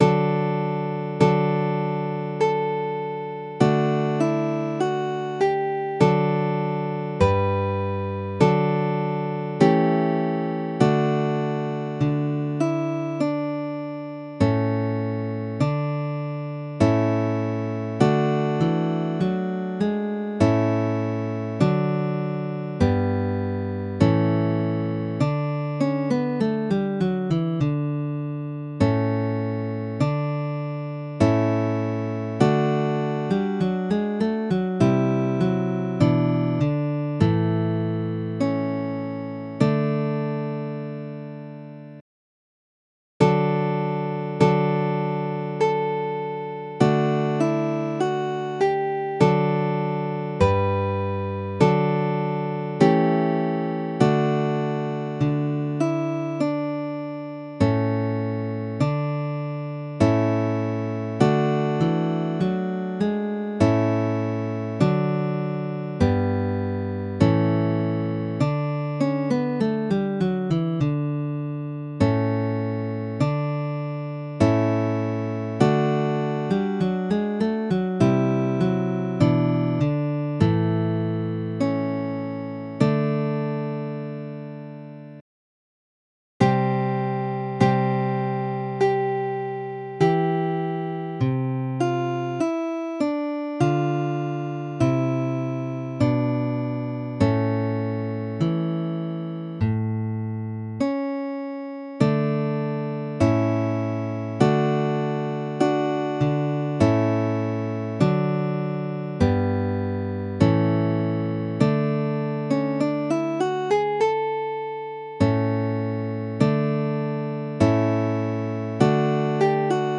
Midi音楽が聴けます 1 90円